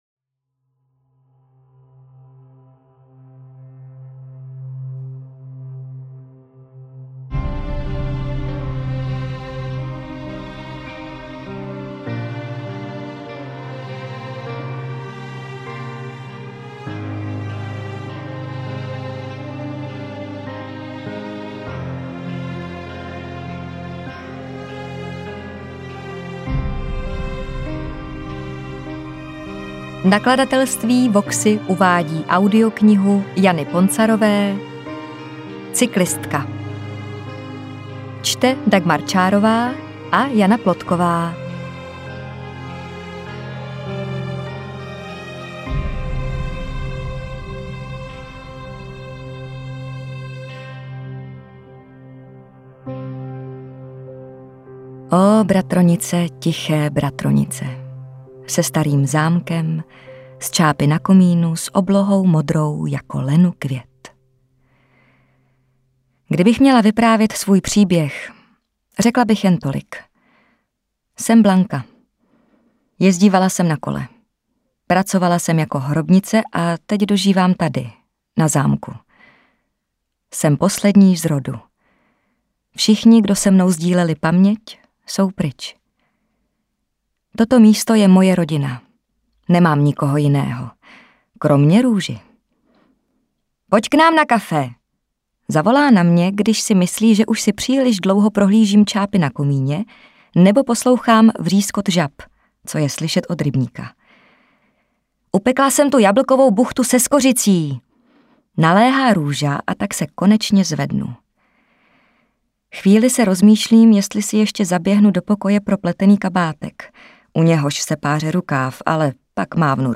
Interpreti:  Dagmar Čárová, Jana Plodková
AudioKniha ke stažení, 56 x mp3, délka 11 hod. 37 min., velikost 633,4 MB, česky